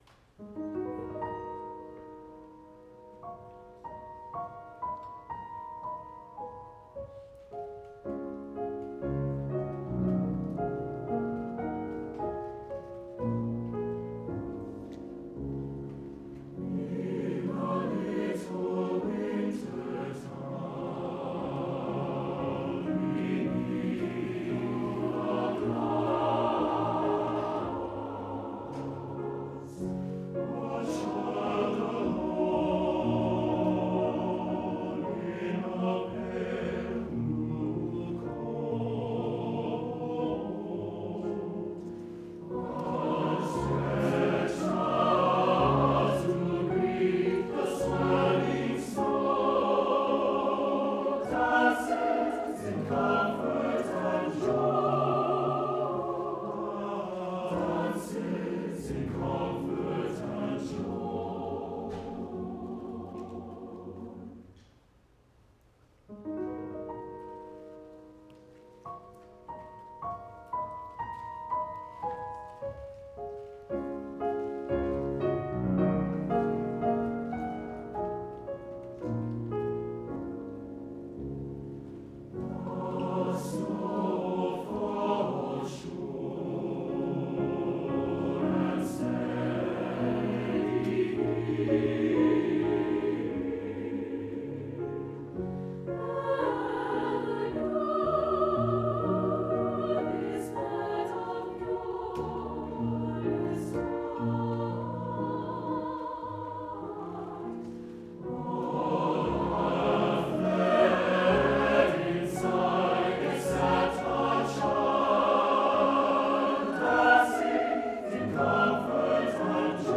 An original song in the spirit of a Christmas carol.
SATB chorus and piano